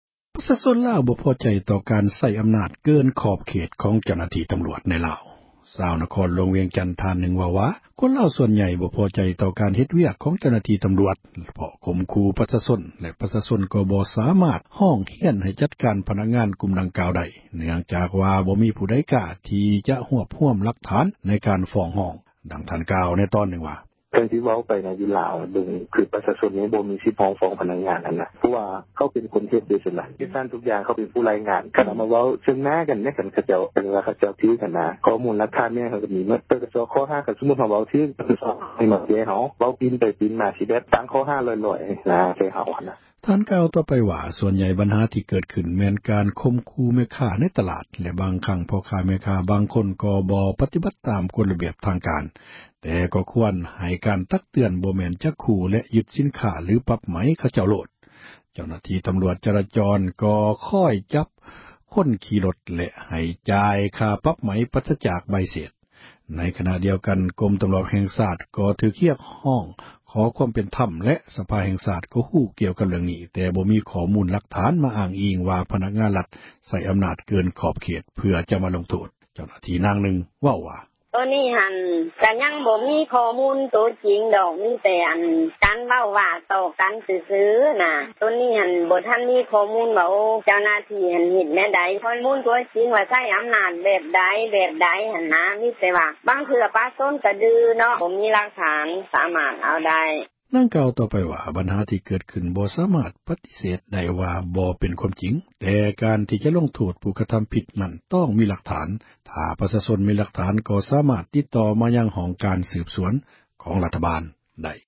ເຈົ້າຫນ້າທີ່ ນາງນຶ່ງ ເວົ້າວ່າ: